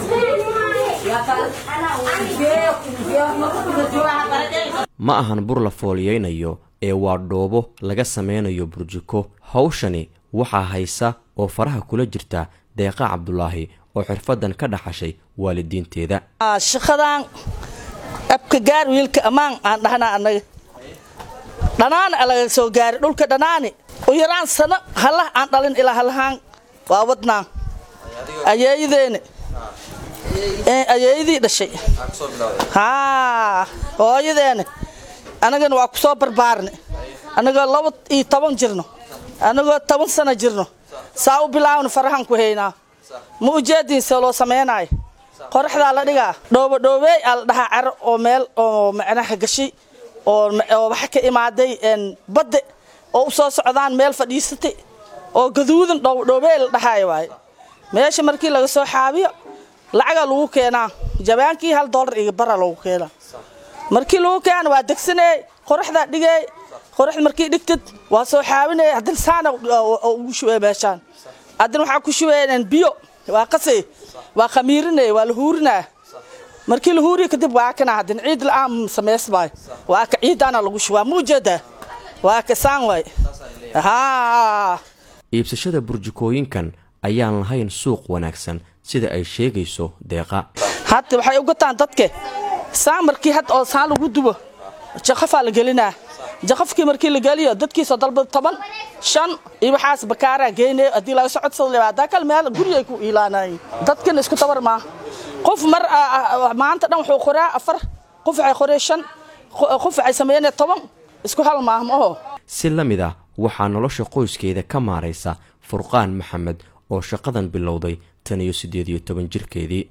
Haweenkan qaar ayaa sheegaya in ay danta ku kallifto in ay mararka qaar beddelaan kuwa laga keeno dibadda oo ay sameeyaan naqshad u eg, warbixintan ayey kaga sheekeynayaan sida ay ku sameeyaan Burjikooyinka iyo heerarka la marsiiyo.
Warbixinta-Burjikooyinka-1.mp3